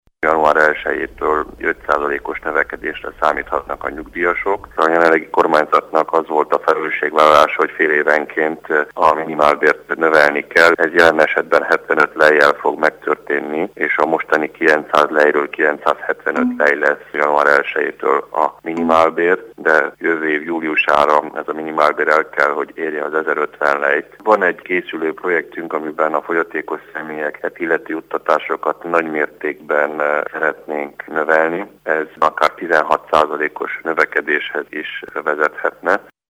Hallgassa meg az államtitkár nyilatkozatát!
Horvath_Levente_minimalber.mp3